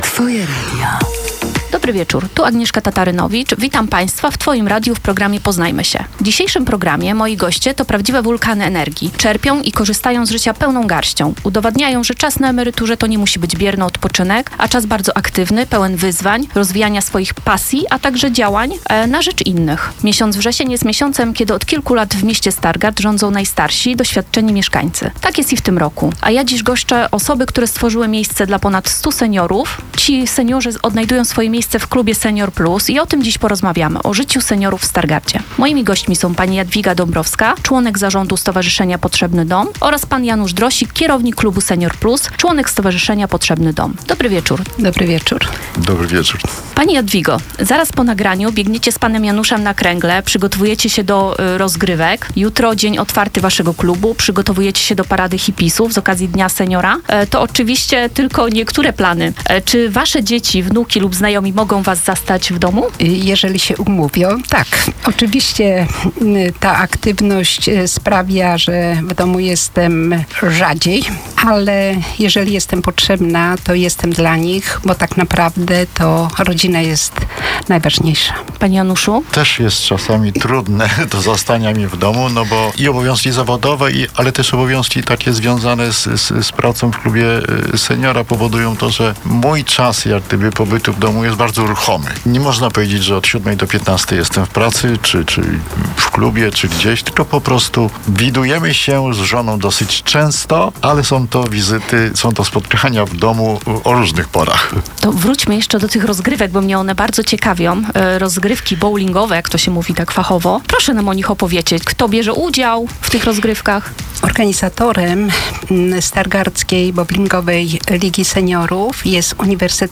zaprasza na audycję